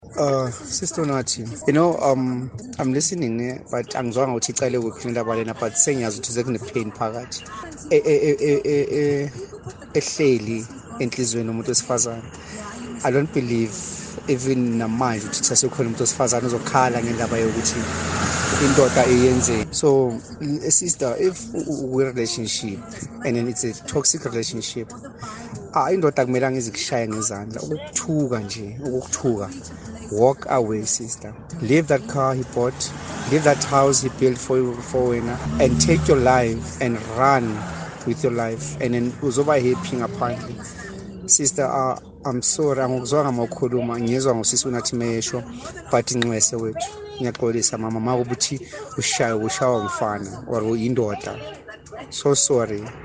The voicenote ended with the caller in tears which left an otherwise cheerful Unathi with her voice breaking.
Listen to Unathi’s powerful message to the woman here: